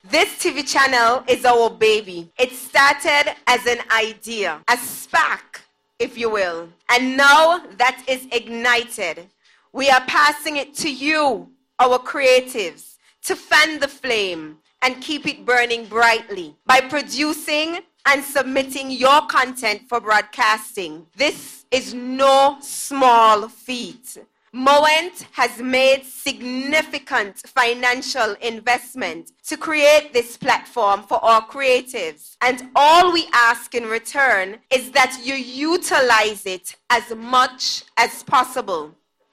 Minister of Entertainment, Hon. Akilah Byron-Nisbett gave these remarks at the official ceremony on July 1st.